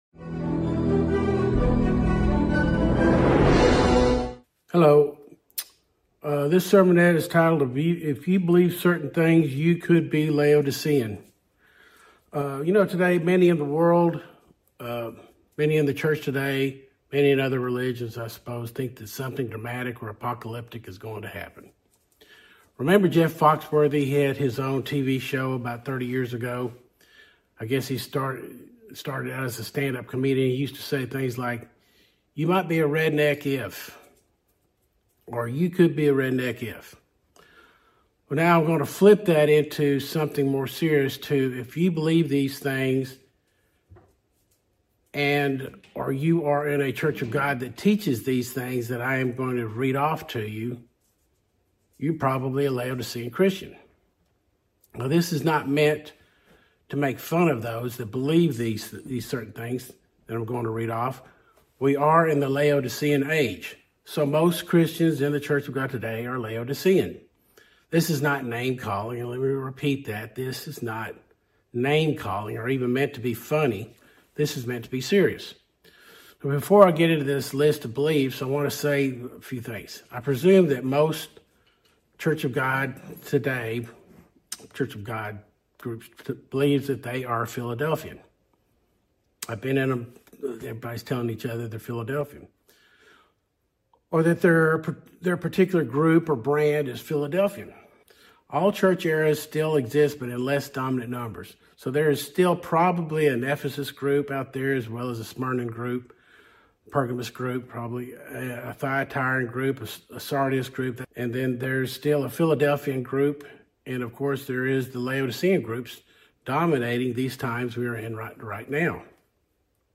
In this sermonette, we explore a challenging but important question: Are you holding beliefs that could identify you as Laodicean?